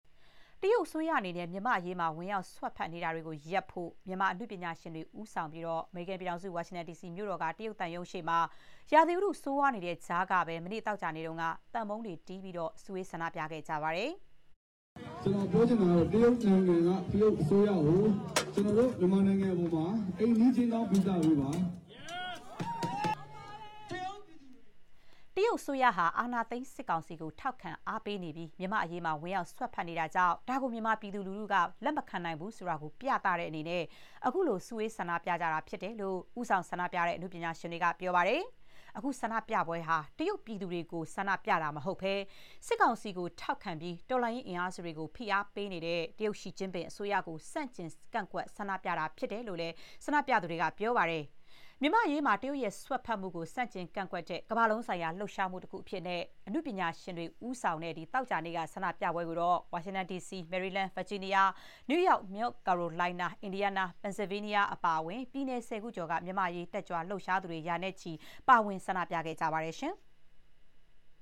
အမေရိကန်ဆိုင်ရာ တရုတ်သံရုံးရှေ့ ဆန္ဒပြနေကြတဲ့ မြန်မာများ (ဇန်နဝါရီ ၃၊ ၂၀၂၅)
တရုတ်အစိုးရအနေနဲ့ မြန်မာ့အရေးမှာ ဝင်ရောက် စွက်ဖက်နေတာတွေ ရပ်ဖို့ မြန်မာ အနုပညာရှင်တွေ ဦးဆောင်ပြီး အမေရိကန် ပြည်ထောင်စု ဝါရှင်တန်မြို့တော်က တရုတ်သံရုံးရှေ့မှာ ရာသီဥတု ဆိုးရွားနေတဲ့ကြားကပဲ သောကြာနေ့က သံပုံးတွေတီးပြီး စုဝေး ဆန္ဒပြခဲ့ကြပါတယ်။